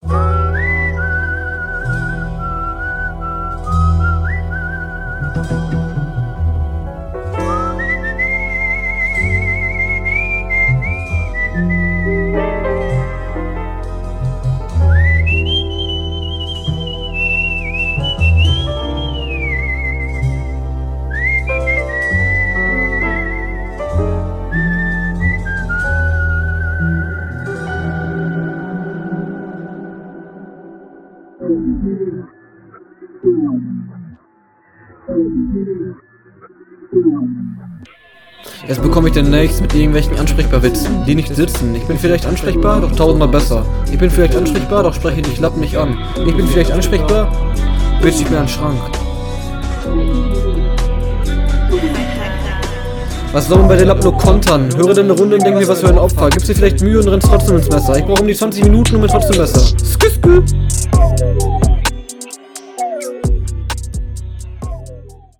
Oh, du bist durchgehend Off-Beat.